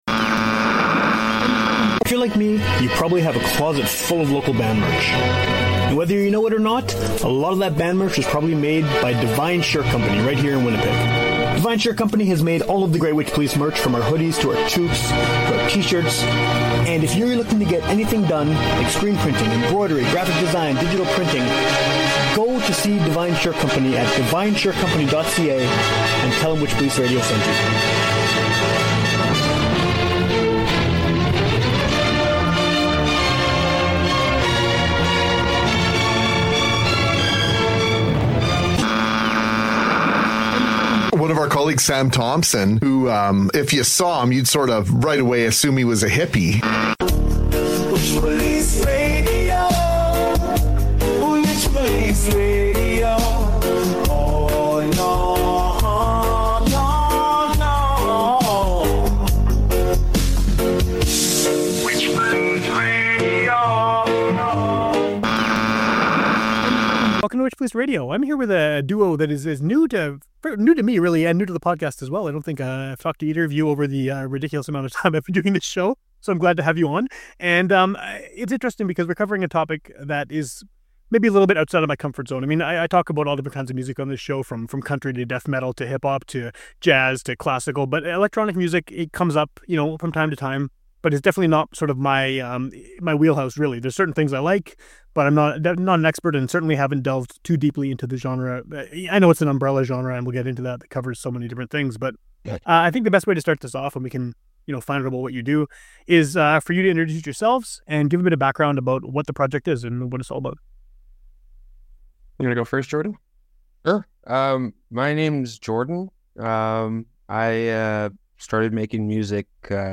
A fun conversation on this one with the duo behind electronic project Peacocking!